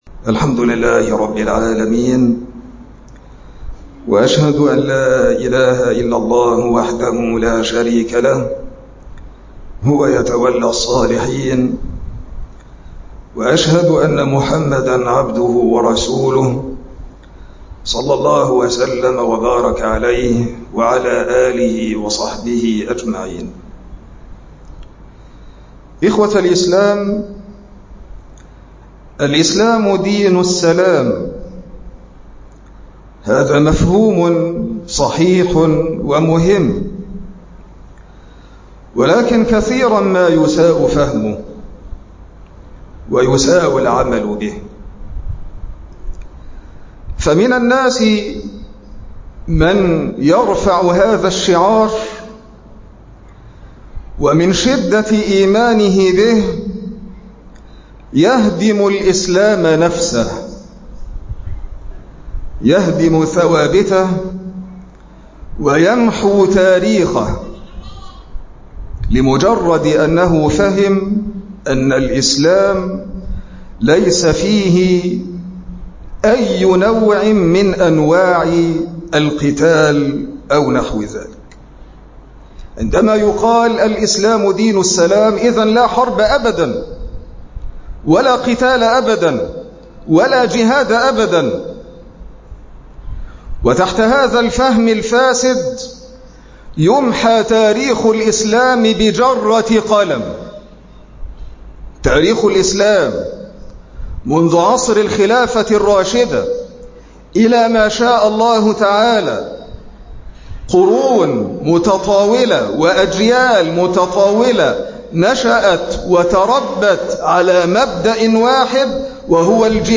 مقطع من خطبة الجمعة (الإسلام دين السلام)